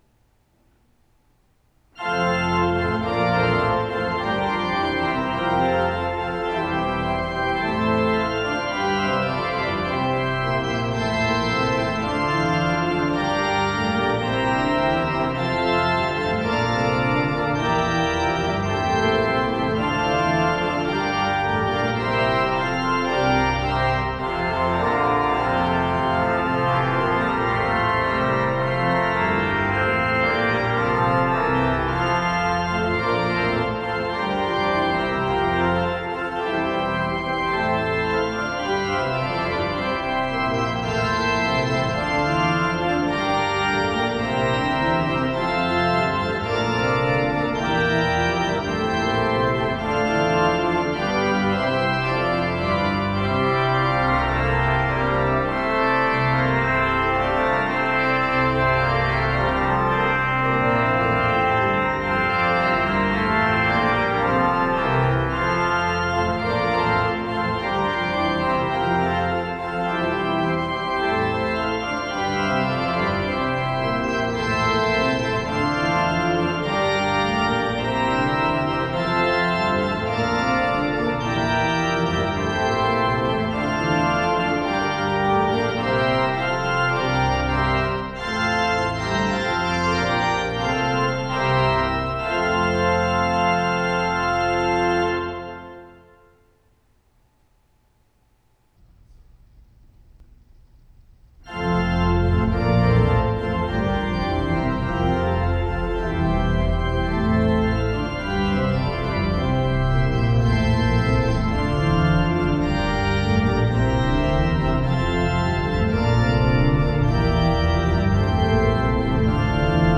Tetrahedral Ambisonic Microphones
Listener Survey Sample #1 (short): Chamber Winds (06:15) (33MB/file).
Ambisonic W-channel (omnidirectional) only. Segments are switched between the 3 microphones in a quasi-random order.